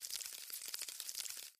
Bug Movement